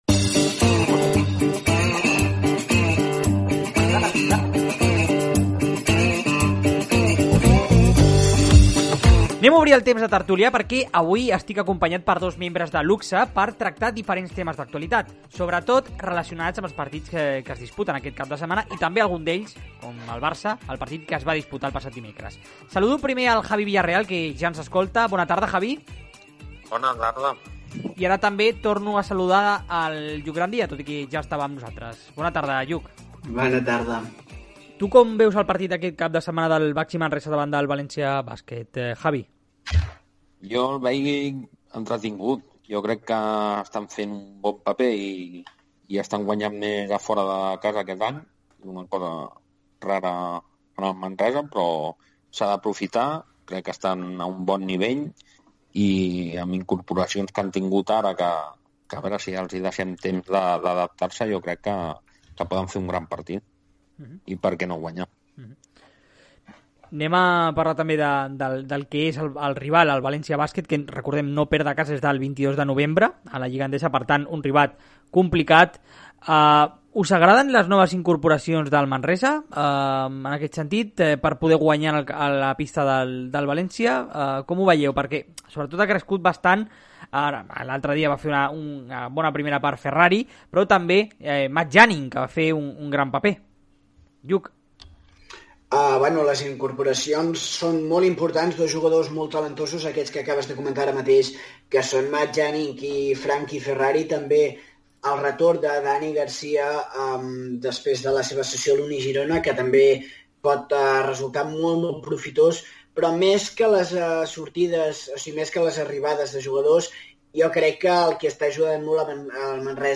Tertúlia esportiva